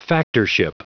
Prononciation du mot factorship en anglais (fichier audio)
Prononciation du mot : factorship